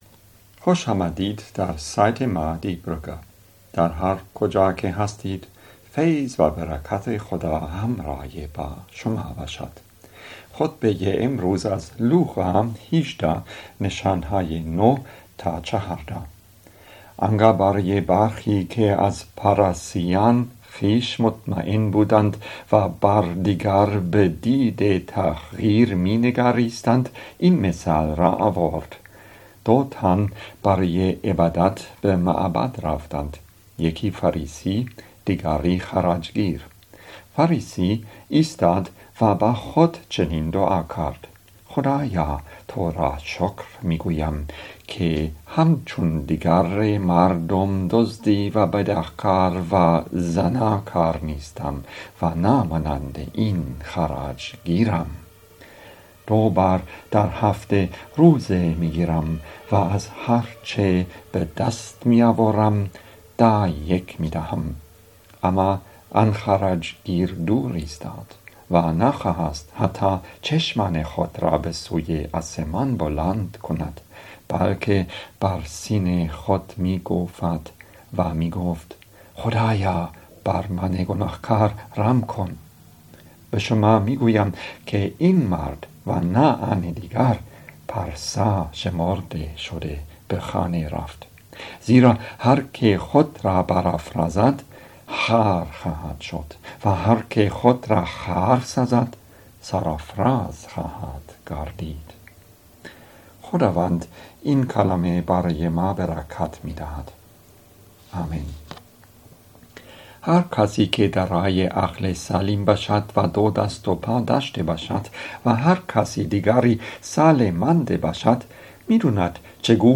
Predigten im Kirchenjahr 2019/2020